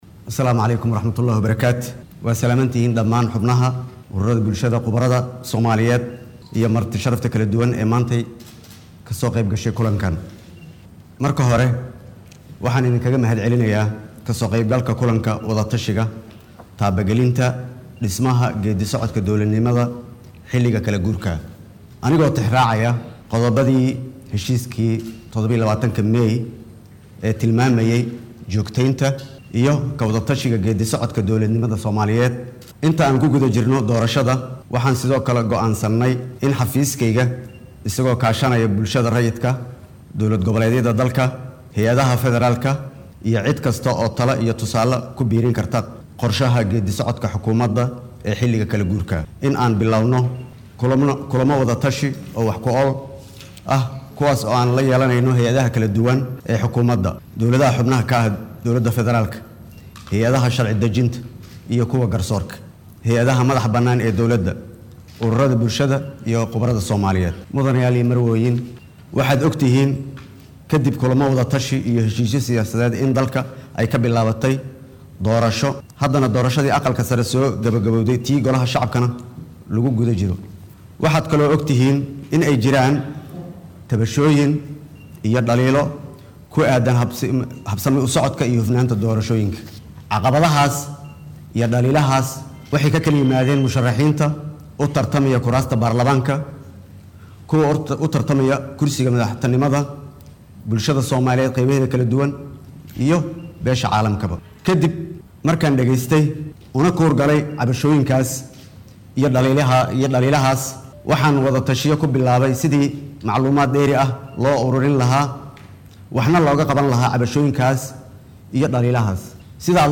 Xilli uu Maanta shir looga hadlayo arrimaha kala guurka ka furay Muqdisho ayuu sheegay in ay ka go’an tahay in ay saxaan wixii khaladaad ah ee jira sarana u qaadaan hufnaanta iyo habsami u socodka doorashada.